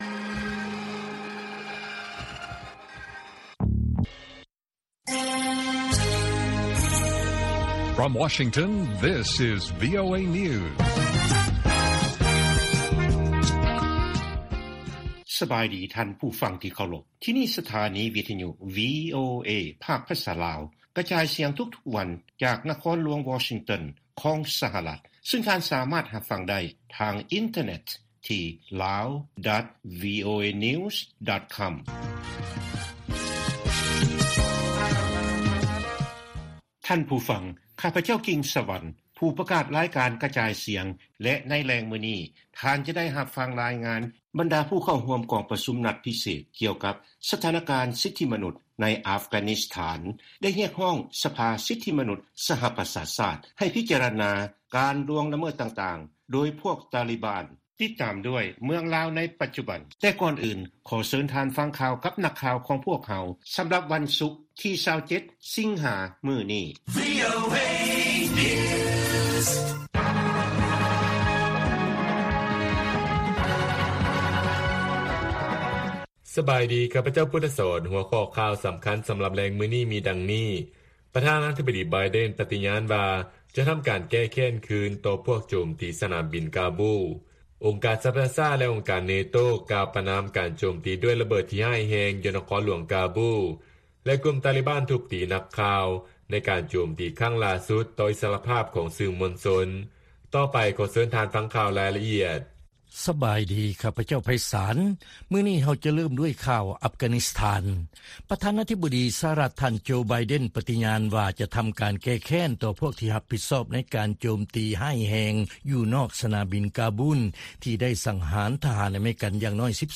ລາຍການກະຈາຍສຽງຂອງວີໂອເອ ລາວ: ແຮງງານລາວ ທີ່ກັບຄືນມາລາວຈາກໄທ ເປັນສາເຫດເຮັດໃຫ້ ມີການລະບາດຂອງໄວຣັສ ໂຄວິດ-19 ຮອບໃໝ່
ວີໂອເອພາກພາສາລາວ ກະຈາຍສຽງທຸກໆວັນ.